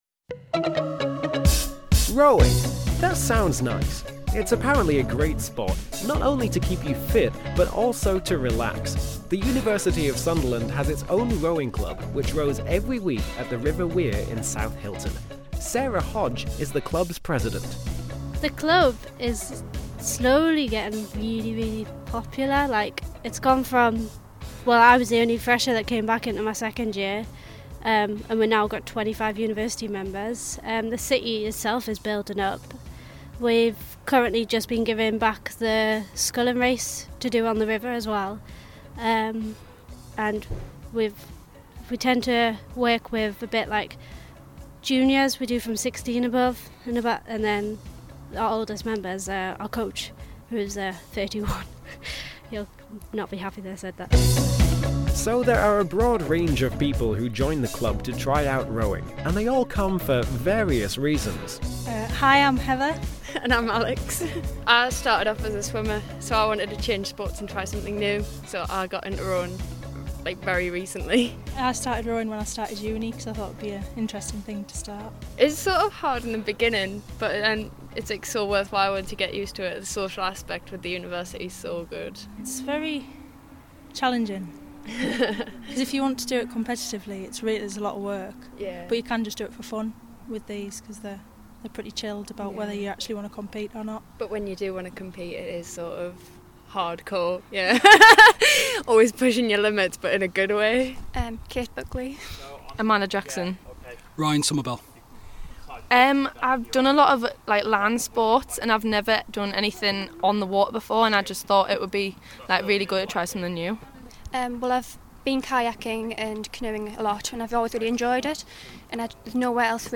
FitFreshers went along to South Hylton to find out about the University’s rowing club.
Other members speak about how they got into rowing about how interesting and challenging it is to learn when you are a beginner.
Current members speak about how welcoming everyone else was to them when they first joined.